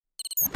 На этой странице представлены звуки мин – от глухих подземных взрывов до резких срабатываний нажимных механизмов.
Электронный сигнал активации мины